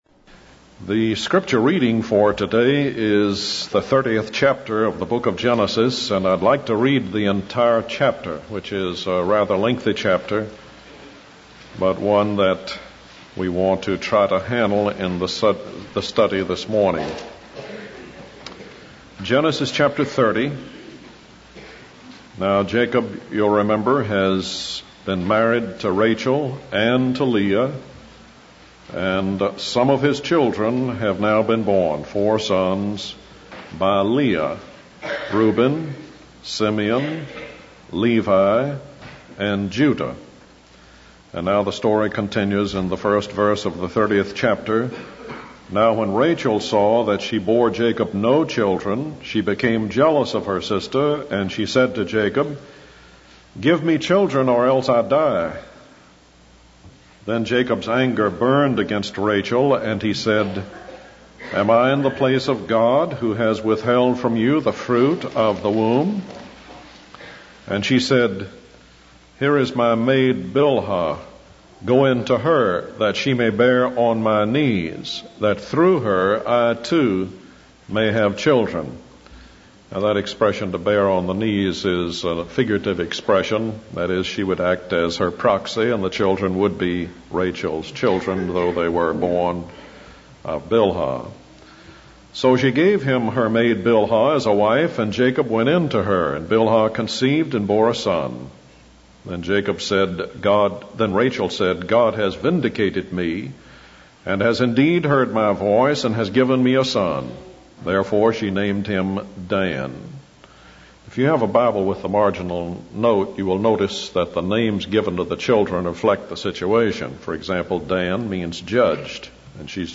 In this sermon, the preacher focuses on Genesis chapter 30, which explores the story of Rachel and Leah and the fruit of polygamy.